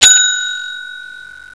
She is my infamous bell.
She has resonance.
bell.wav